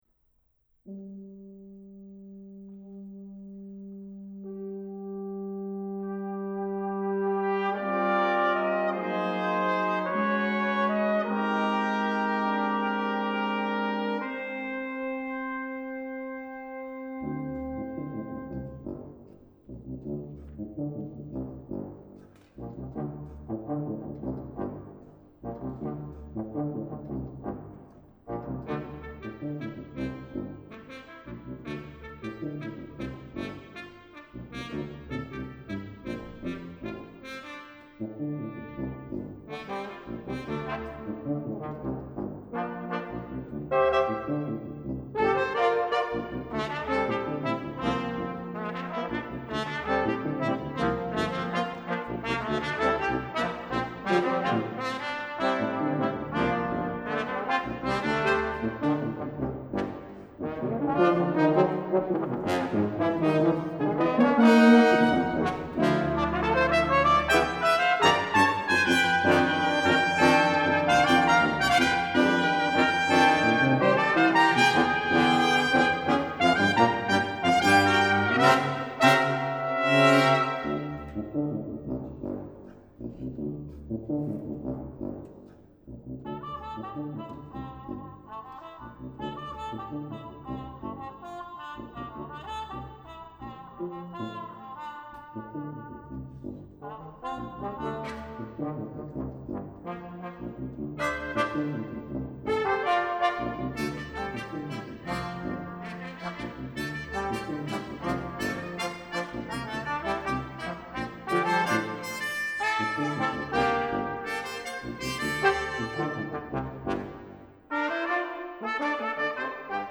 I just received the clips from Triton's performance at the first BUTI Wind Ensemble concert in Ozawa Hall at Tanglewood last July. Couple movements from Paquito D'Rivera's Four Pieces for brass quintet.